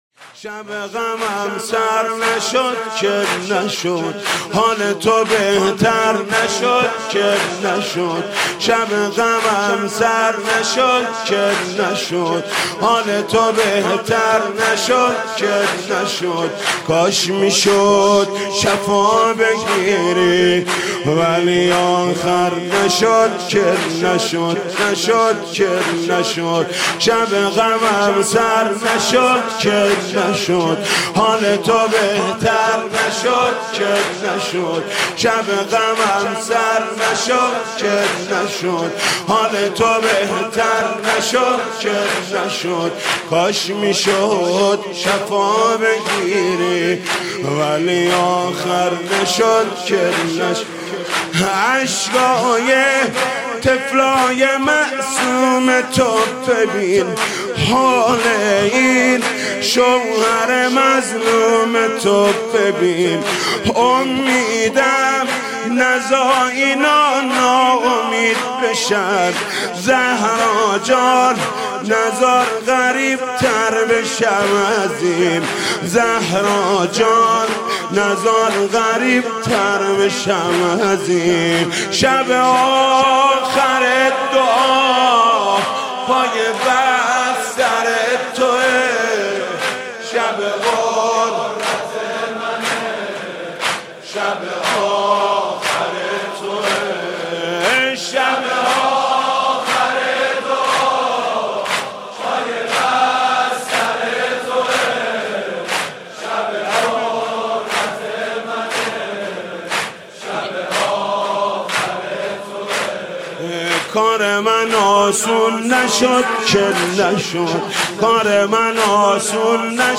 مداحی و نوحه
سینه زنی، شهادت حضرت زهرا(س